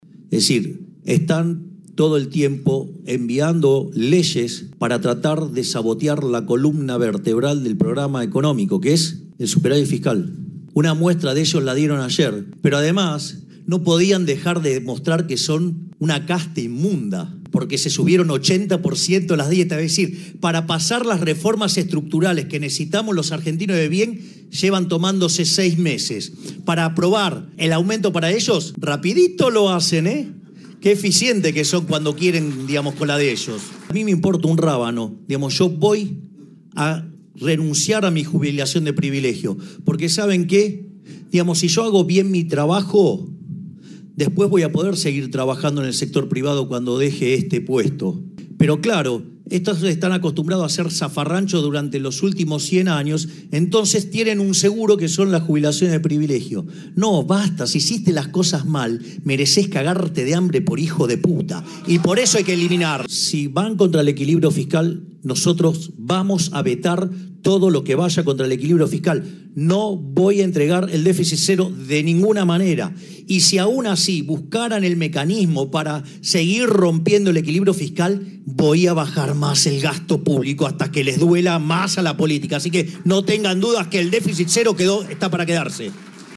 Con tono ciertamente contenido, Milei ratificó que renunciará a su jubilación de privilegio.
AGROACTIVA-MILEI-SOBRE-VETO-A-LEYES.mp3